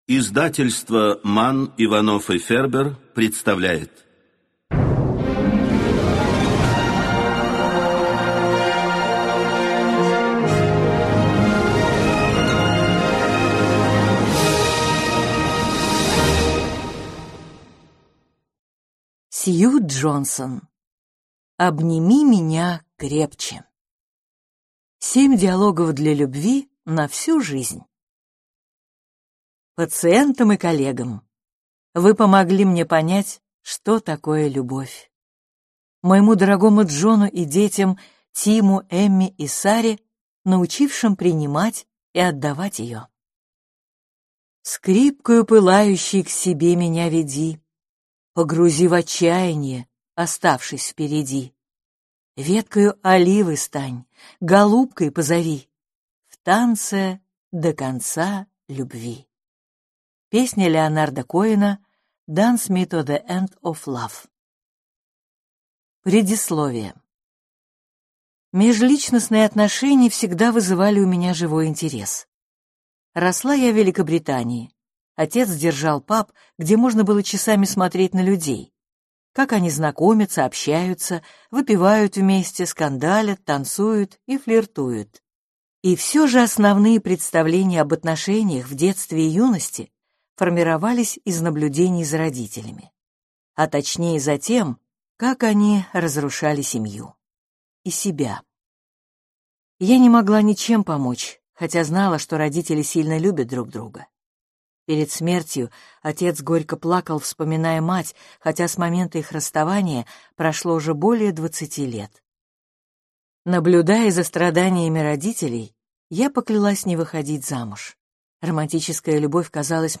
Аудиокнига Обними меня крепче | Библиотека аудиокниг